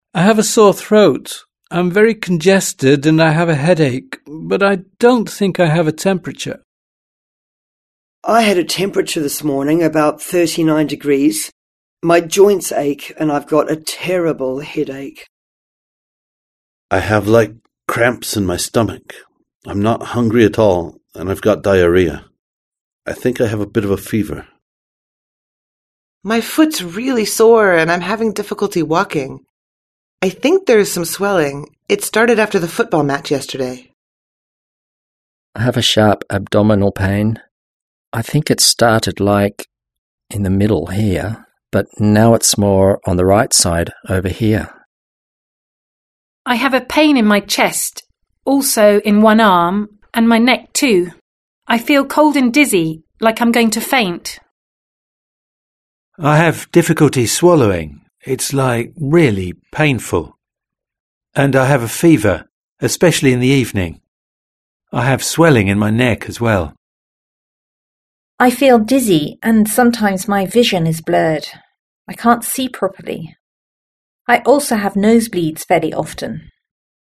Listen to each patient’s symptoms and choose the best diagnosis.